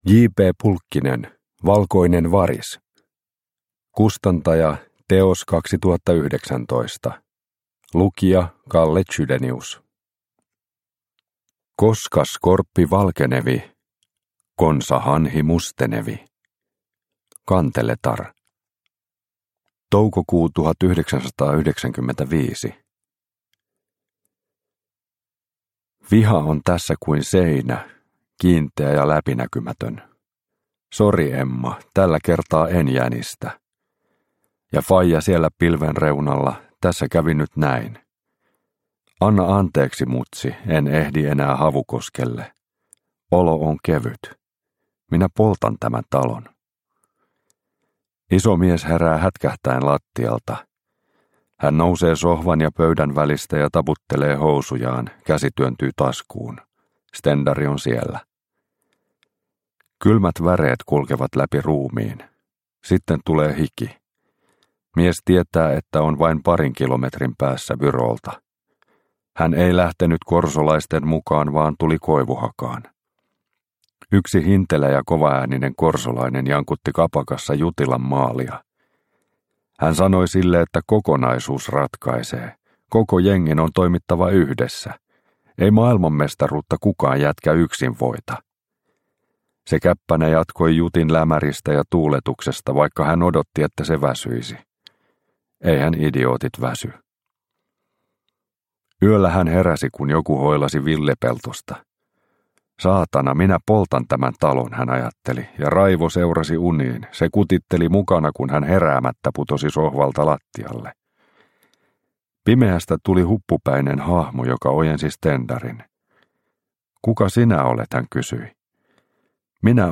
Valkoinen Varis – Ljudbok – Laddas ner